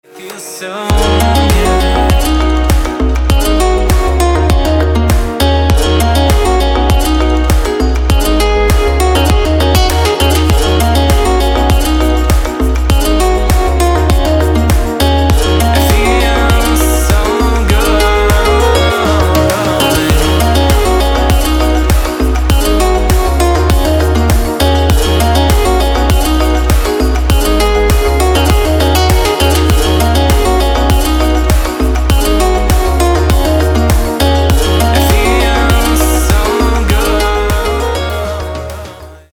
• Качество: 192, Stereo
гитара
deep house
dance
tropical house
Spanish Guitar